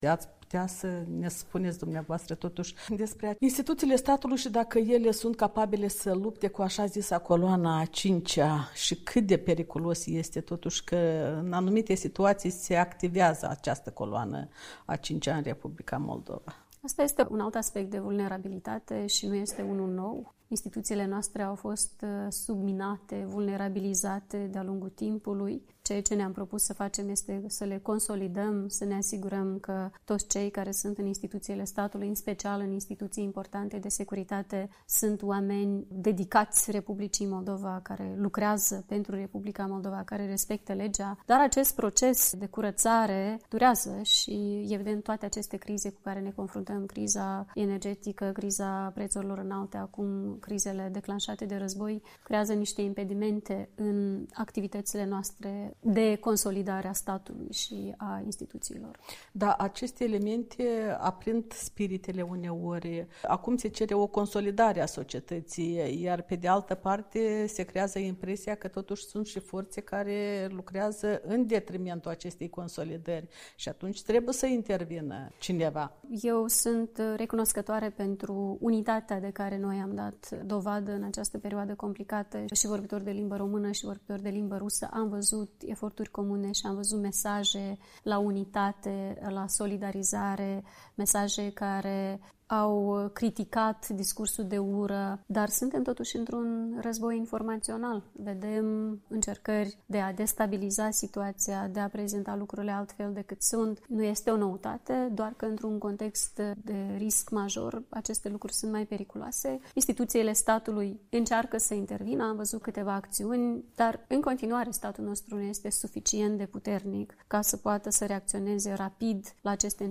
Președinta Maia Sandu a apărat decizia Chișinăului de a nu se alinia sancțiunilor occidentale impuse Rusiei pentru invadarea Ucrainei, explicând că Republica Moldova are multiple „vulnerabilități”, atât în planul securității, cât și cel economic. Într-un interviu acordat Europei Libere, șefa...